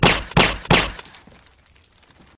While Martin Scorsese may be one of the greatest director of all time, how does this movie get best sound when the bullet that just killed Matt Damon sounded like a freakin’ laser beam shot from a weapon in Star Wars.
the-departed-final-scene-gun-silencer-sound.mp3